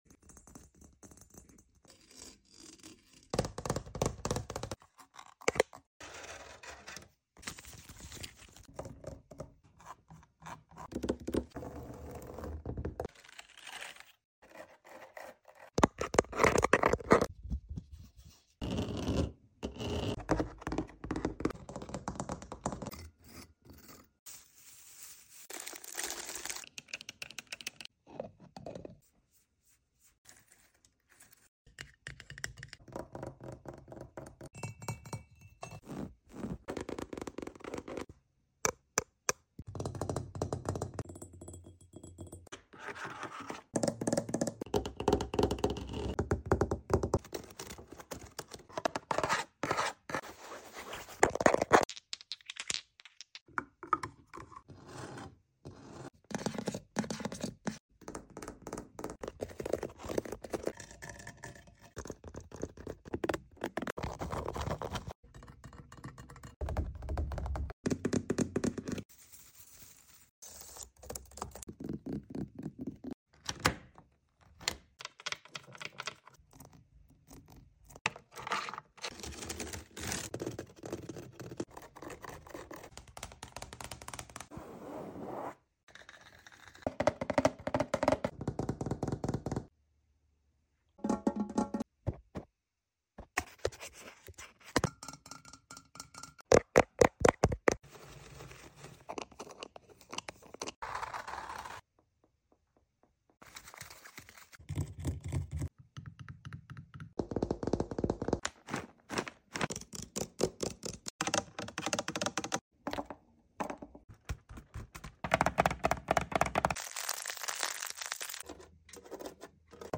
In honor of hitting 100 followers, here is 100 triggers for you☺